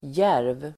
Uttal: [jär:v]